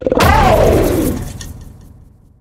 Cri de Koraidon dans sa forme Monture dans Pokémon HOME.
Cri_1007_Monture_HOME.ogg